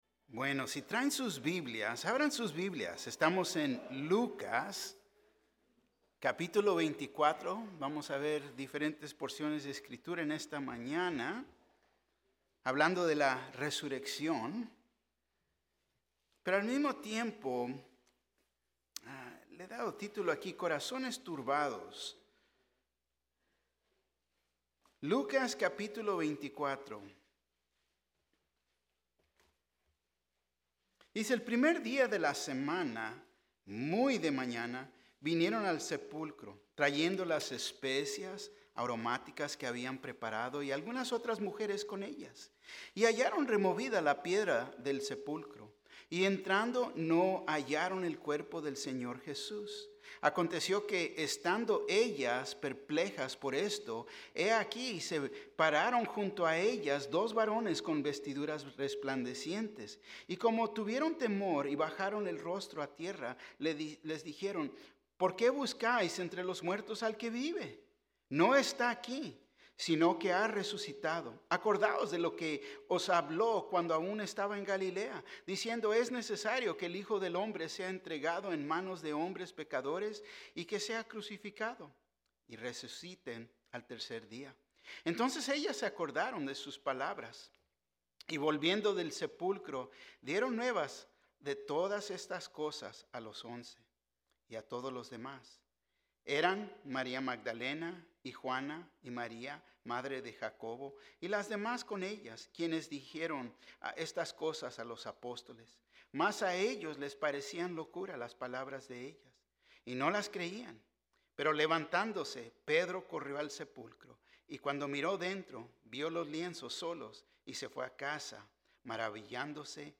Un mensaje de la serie "Estudios Tématicos."